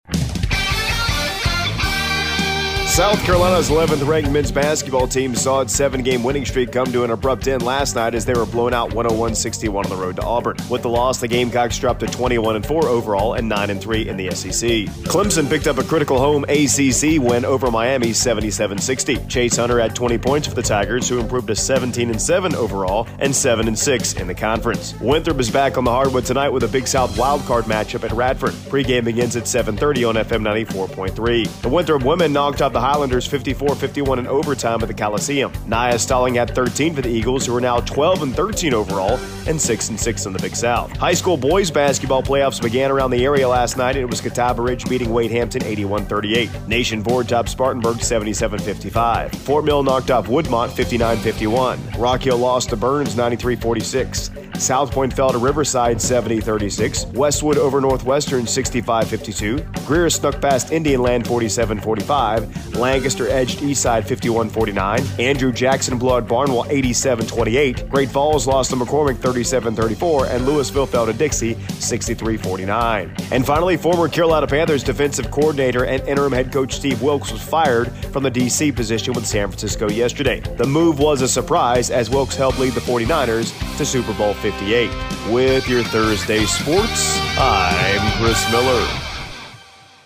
AUDIO: Monday Morning Sports Report